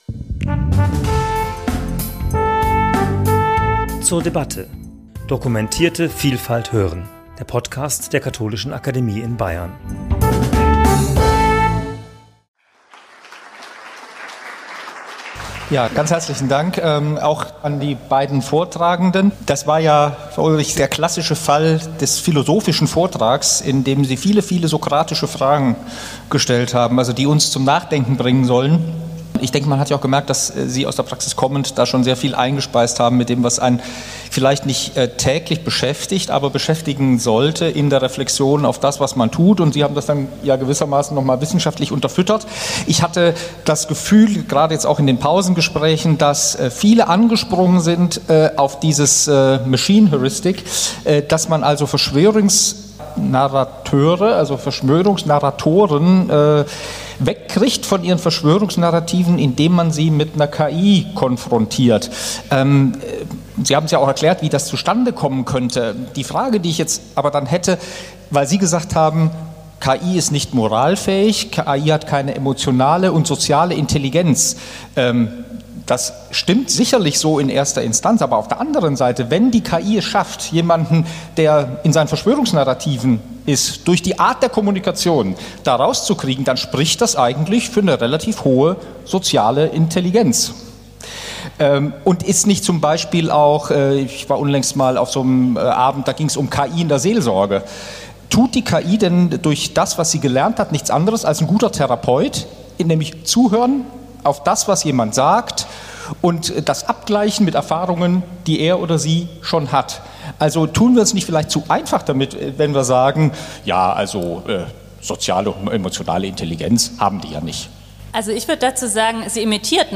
Gespräch zum Thema 'KI in den Medien' ~ zur debatte Podcast